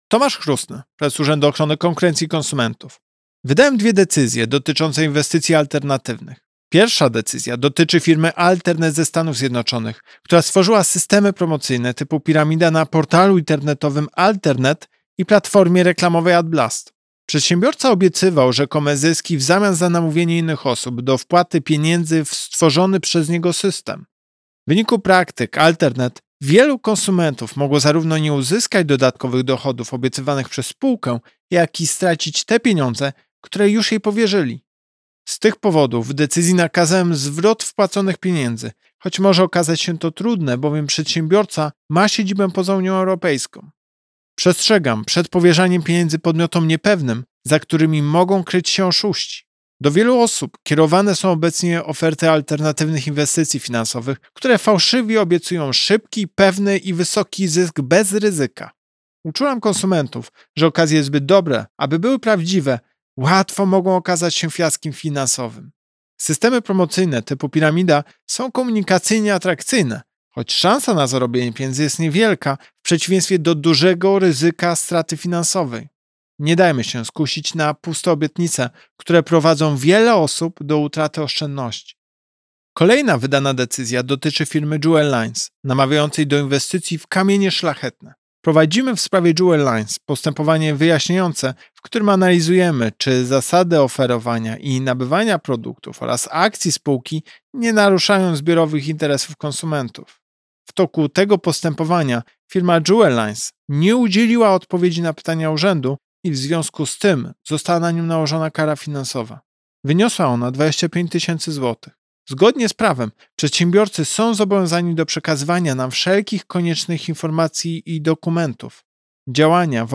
Wypowiedź Prezesa UOKiK Tomasza Chróstnego z 3 lutego 2021 r..mp3